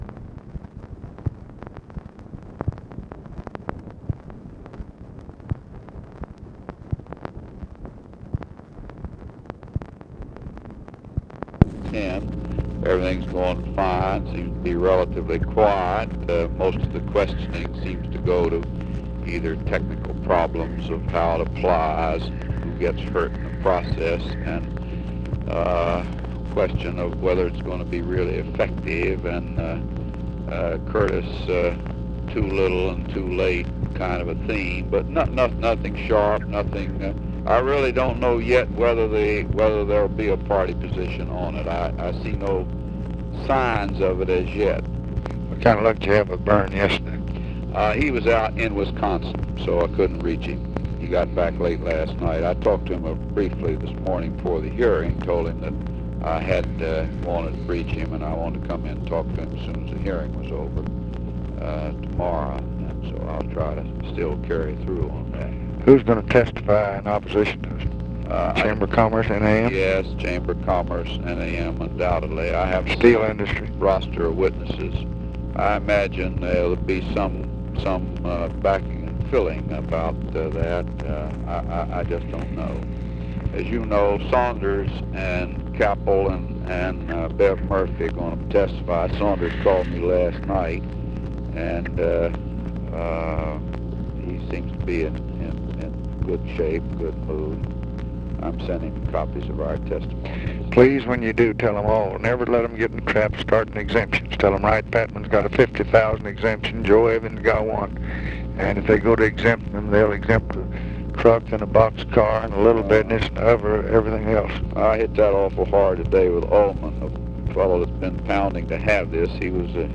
Conversation with HENRY FOWLER, September 12, 1966
Secret White House Tapes